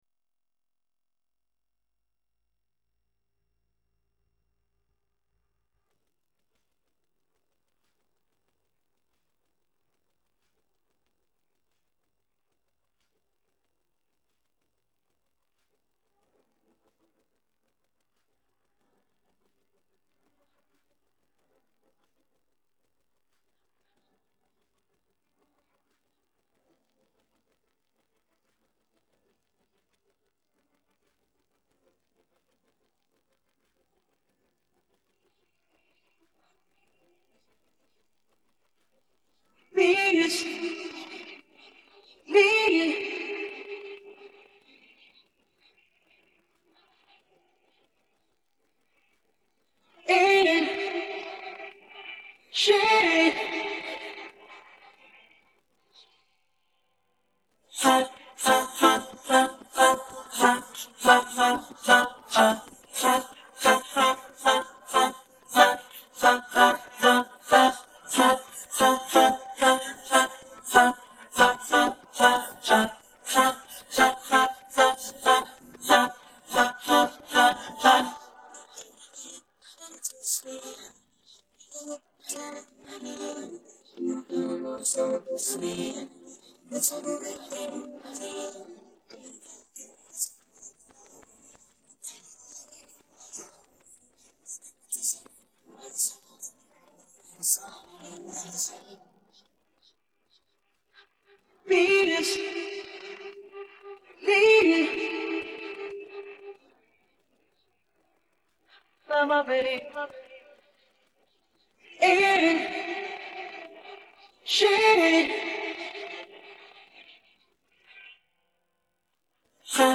人聲部分